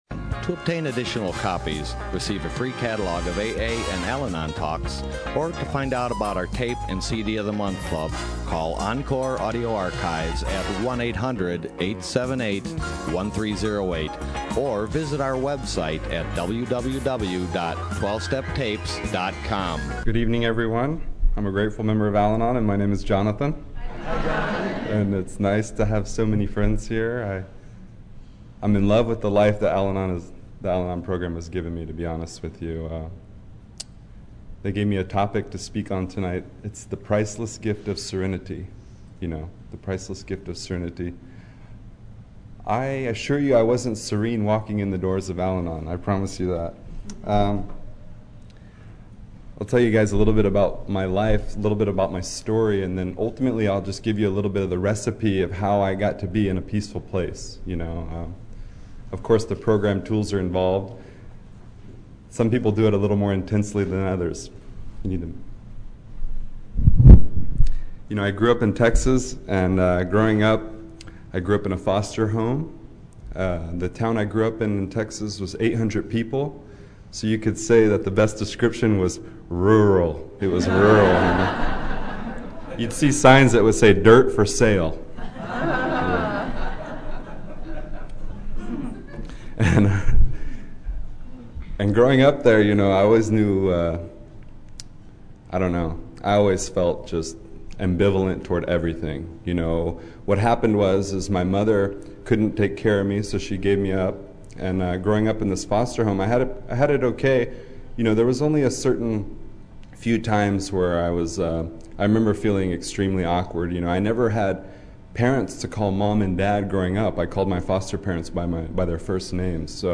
Orange County AA Convention 2014